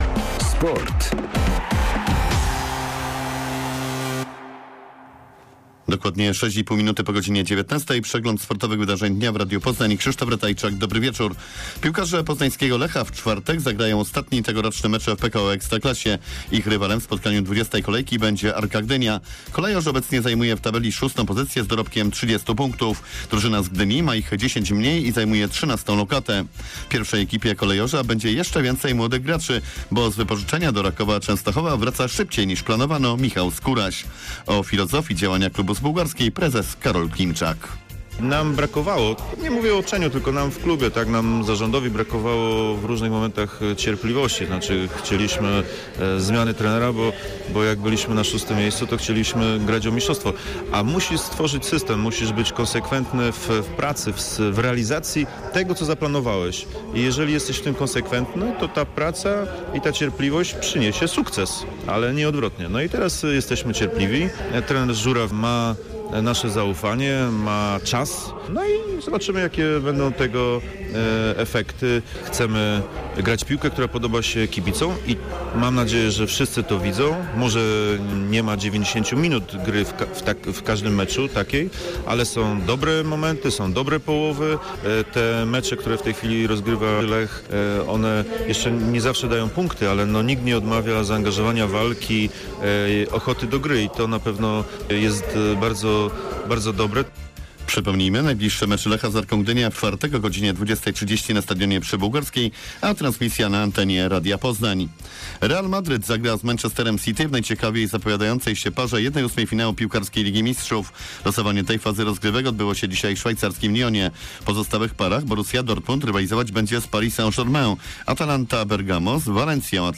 16.12. SERWIS SPORTOWY GODZ. 19:05